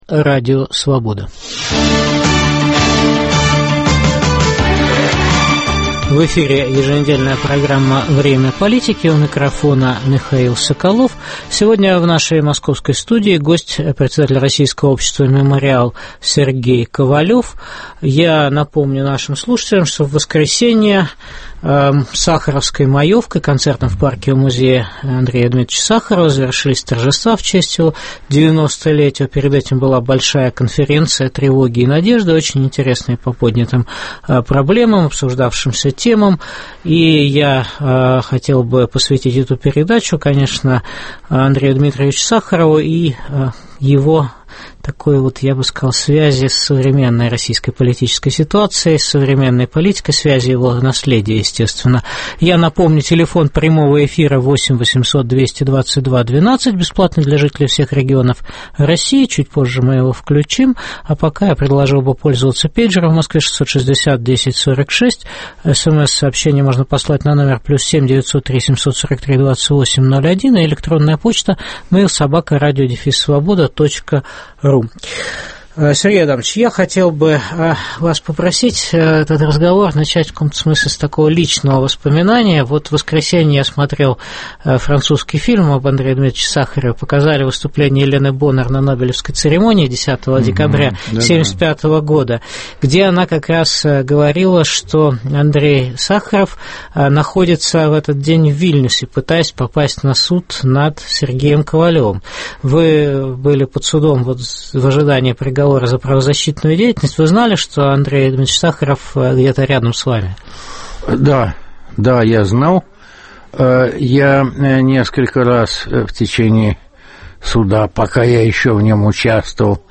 Актуально ли наследие Андрея Сахарова для современной российской политики? В программе в прямом эфире выступит председатель Российского общества "Мемориал" Сергей Ковалев.